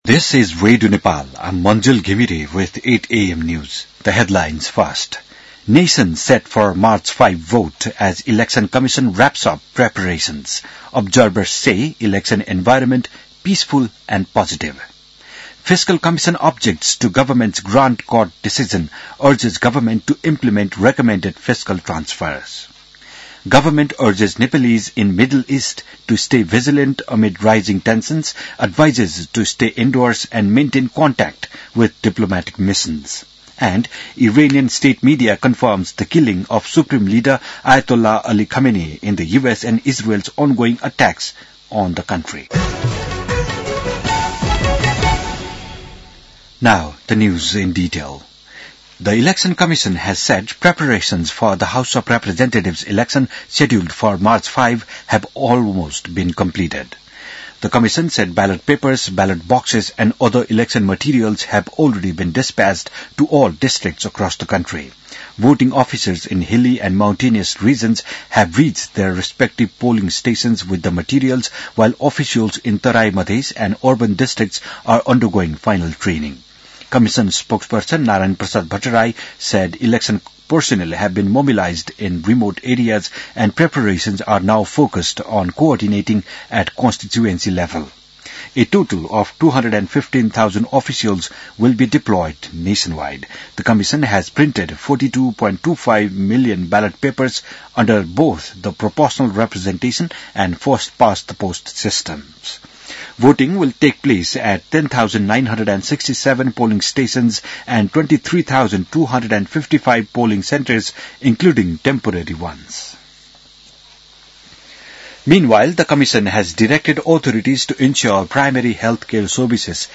बिहान ८ बजेको अङ्ग्रेजी समाचार : १७ फागुन , २०८२